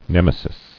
[nem·e·sis]